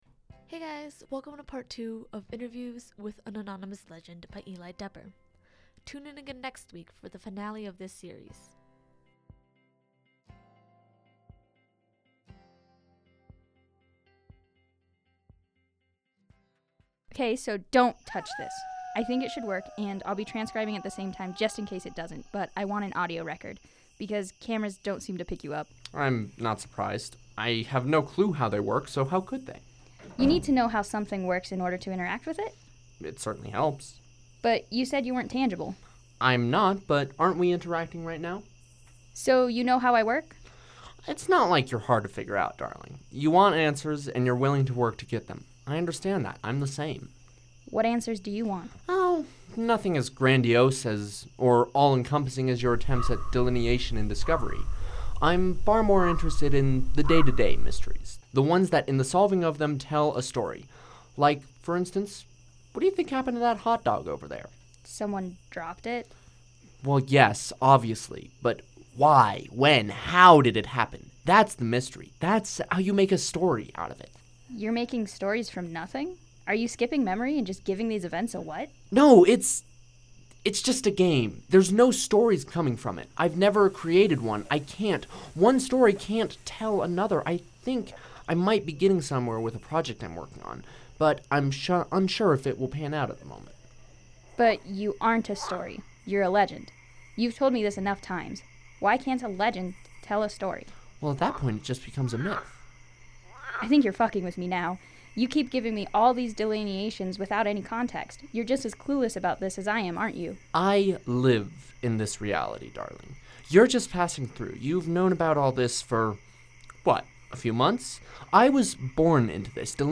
• Interviewer character
• Legend character
• Background music - Purple Planet